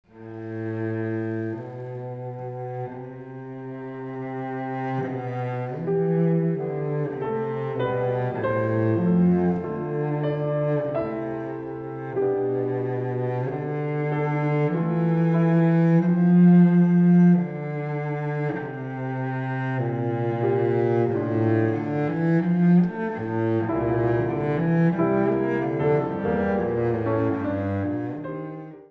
Für Violoncello und Klavier
Neue Musik
Ensemblemusik
Duo
Violoncello (1), Klavier (1)